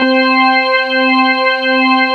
23 ORGAN  -L.wav